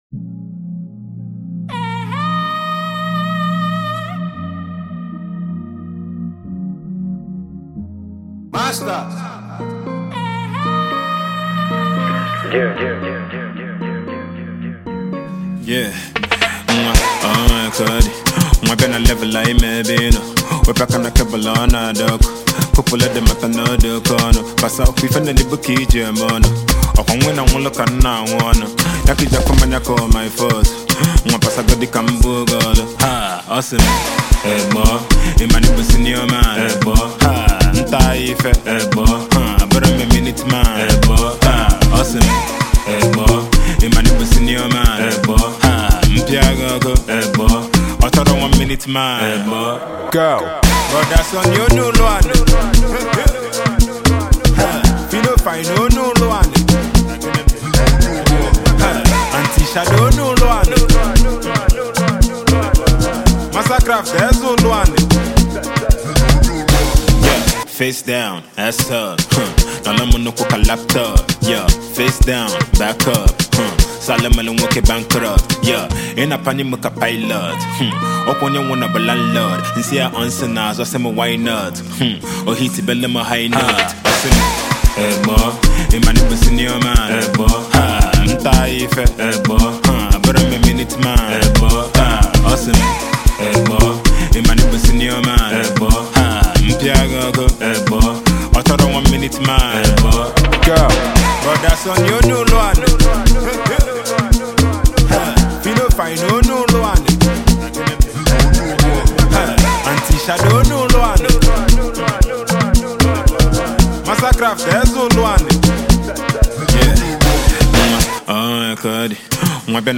Amapiano influenced song